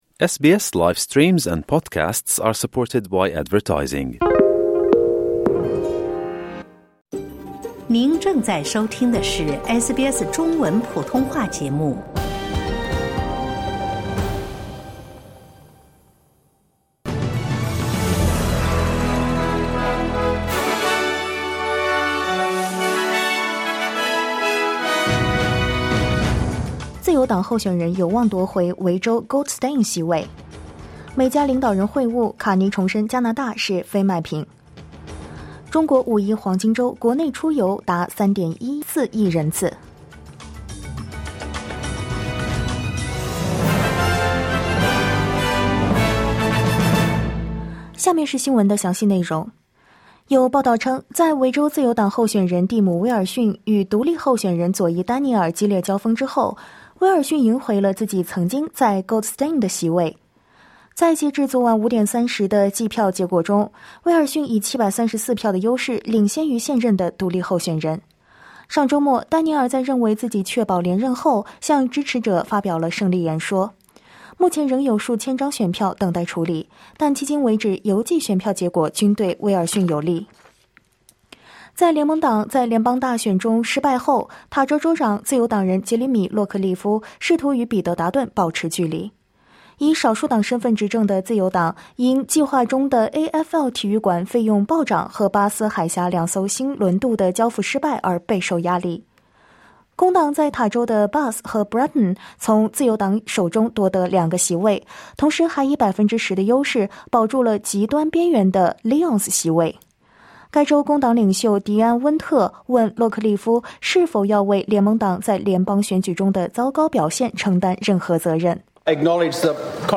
SBS早新闻（2025年5月7日）